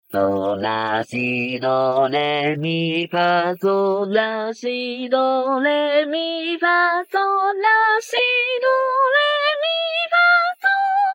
藤咲透明_JPVCV_強がり（power）               DL
收錄音階：G3 B3 D4